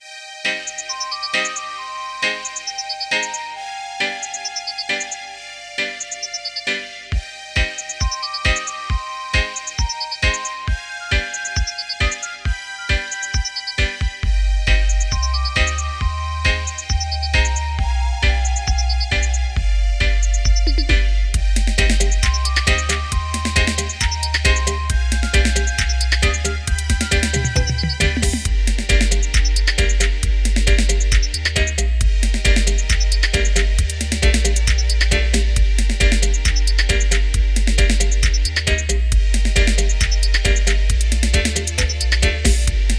The A-side features the riddim
this release brings the 90s-style riddim to life.